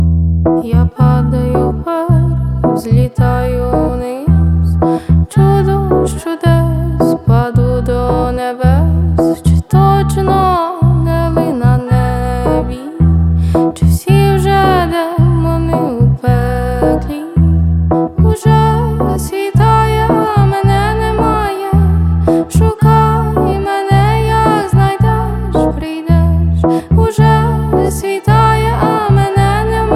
Жанр: Поп / Инди / Украинские
# Indie Pop